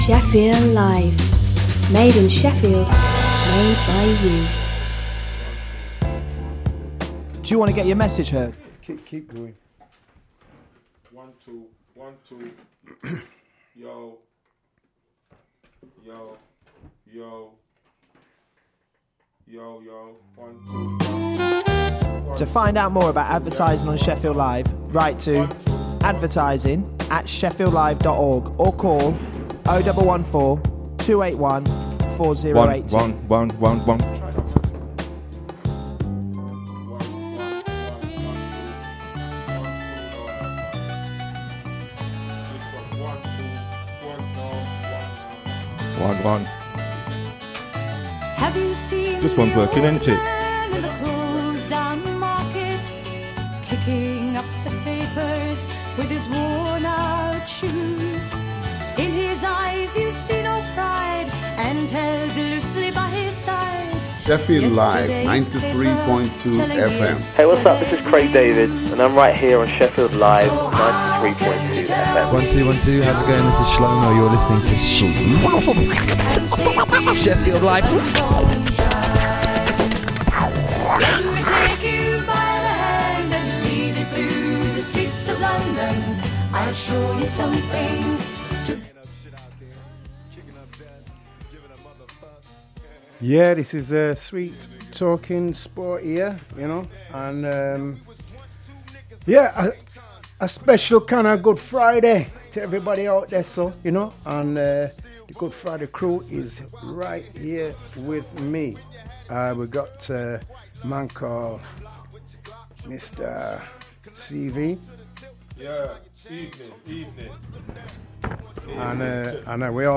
Talking Balls is a tongue in cheek sports chat show looking at the latest sports news and stories, with both interviews, previews and competitions